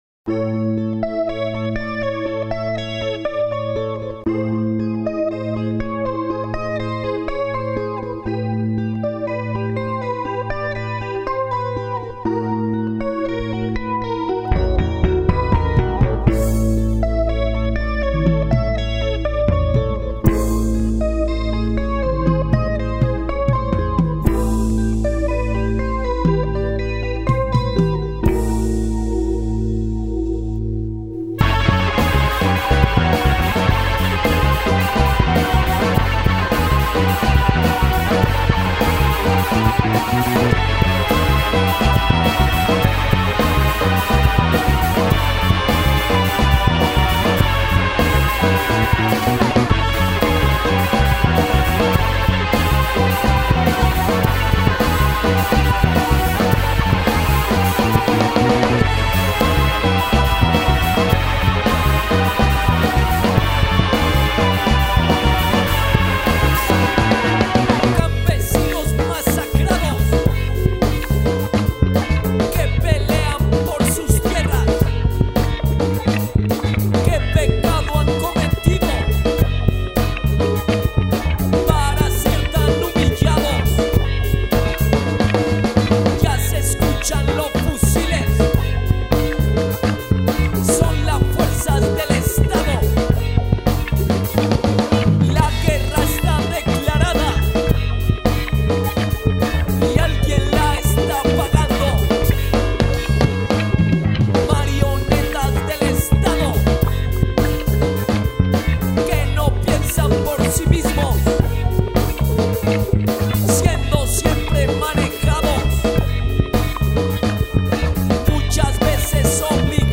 Rock Alternativo